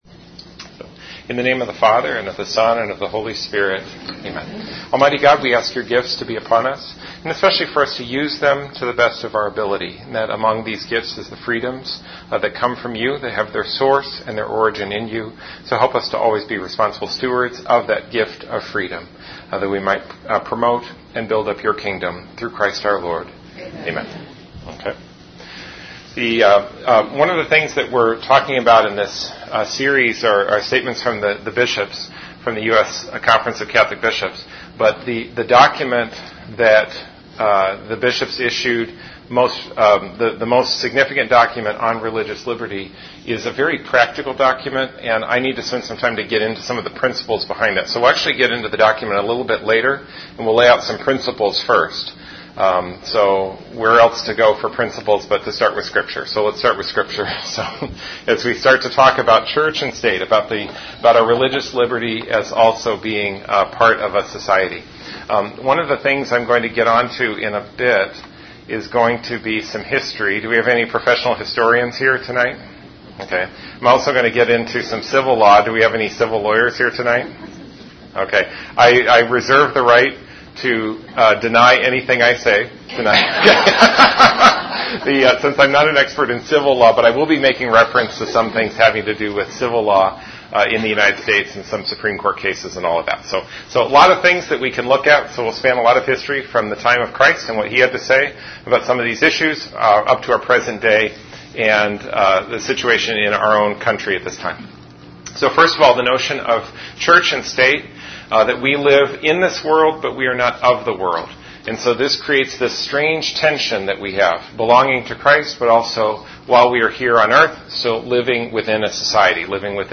The following presentation was given as part of a 2017 adult education series offered at St. Thomas Parish, Peoria Heights, IL.